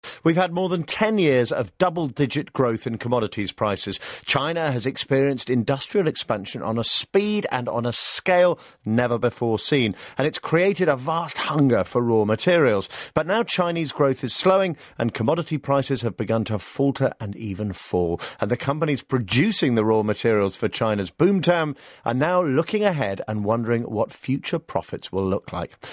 【英音模仿秀】近距离看鲸背山铁矿 听力文件下载—在线英语听力室